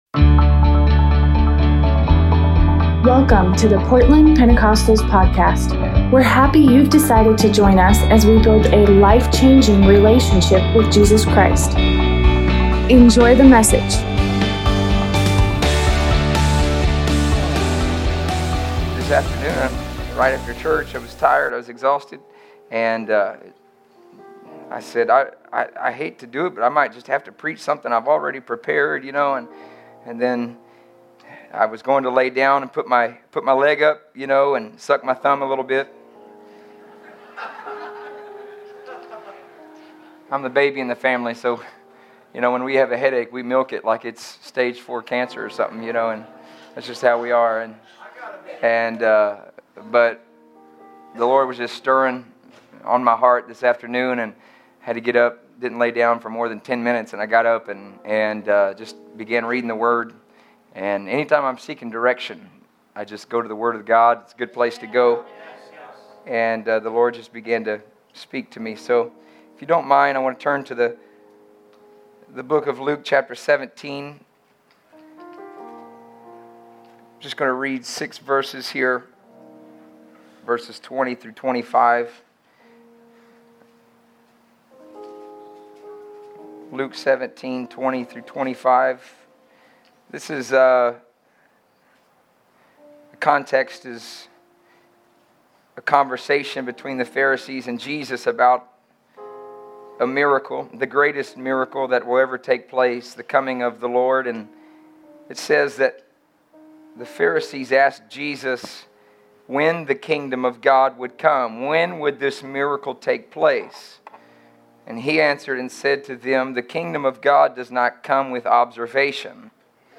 Sunday night sermon